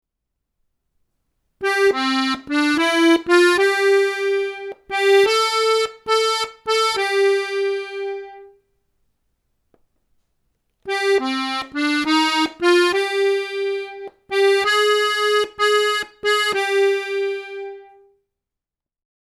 Check the difference listening to the audio!